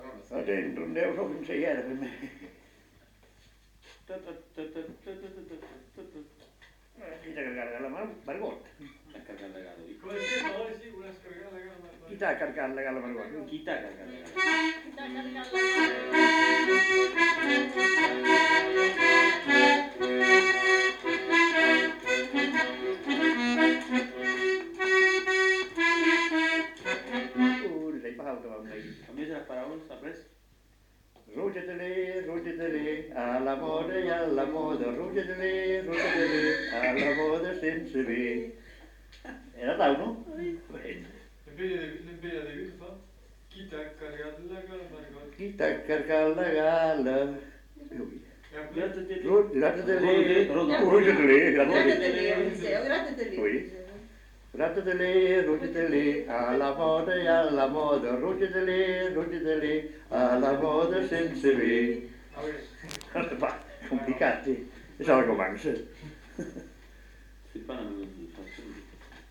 Lieu : Lencouacq
Genre : morceau instrumental
Instrument de musique : accordéon diatonique
Danse : rondeau